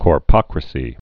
(kôr-pŏkrə-sē)